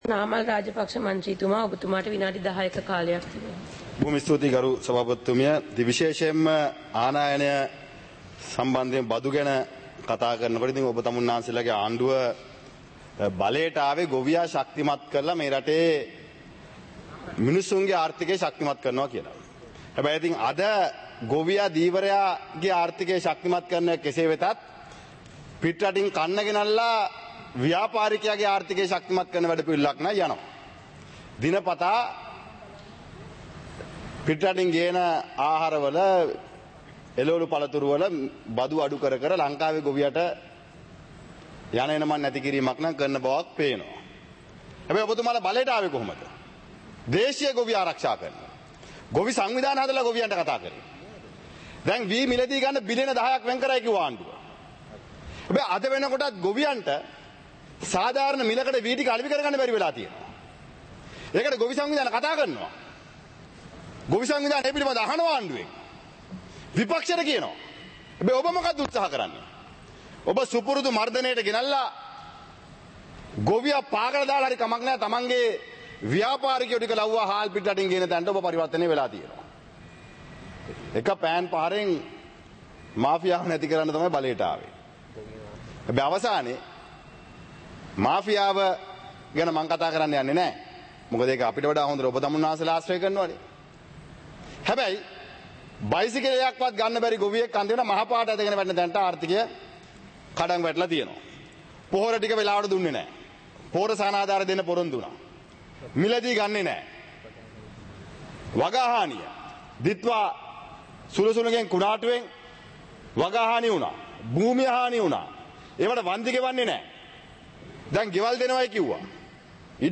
இலங்கை பாராளுமன்றம் - சபை நடவடிக்கைமுறை (2026-02-18)
நேரலை - பதிவுருத்தப்பட்ட